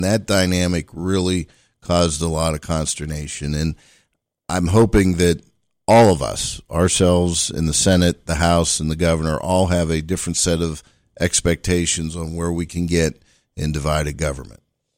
In interviews on Indiana in the Morning last week, State Representative Joe Pittman said that one of the ways to avoid a prolonged budget impasse like last year is to manage expectations on all fronts, referencing last year’s dispute between Democrats and Republicans on a school voucher proposal.